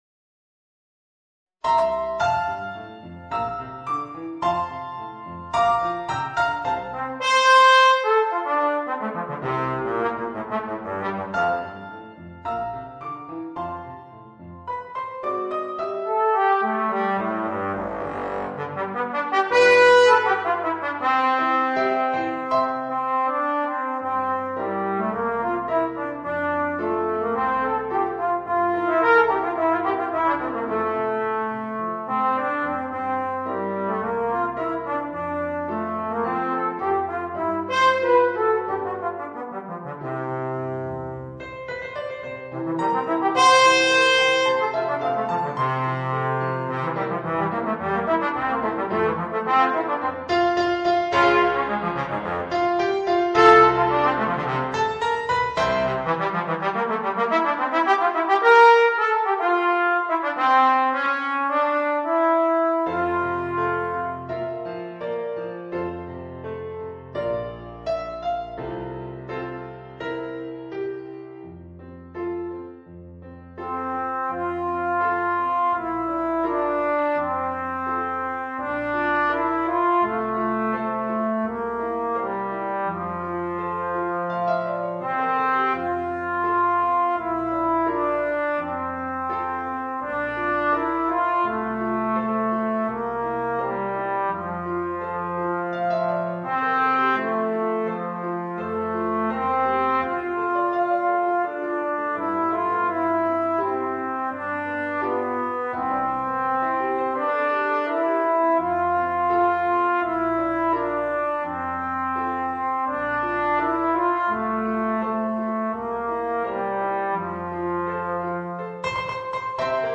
Voicing: Trombone w/ Audio